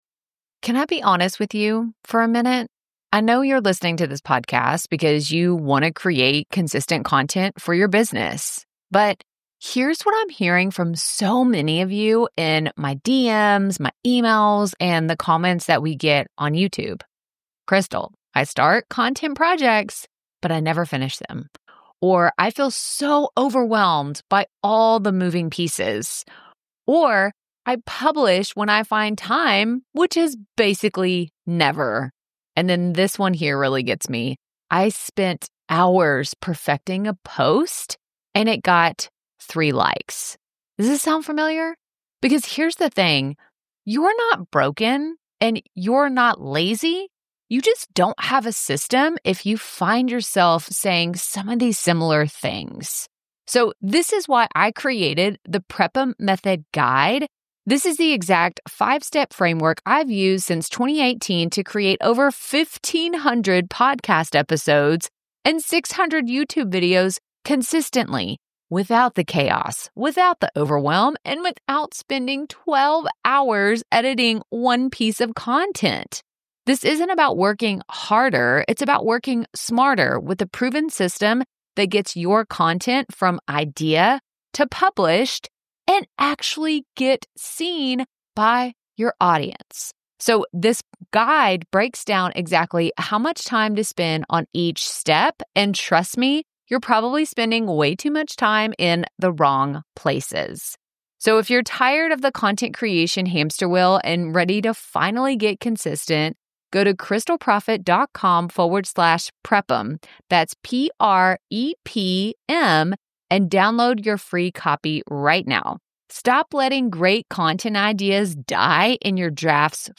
In this behind-the-scenes episode, you'll hear various parts of the trip, including my walk-and-talk thoughts before the event, event secrets I kept (until now), and recaps.